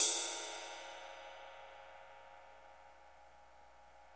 CYM XRIDE 5F.wav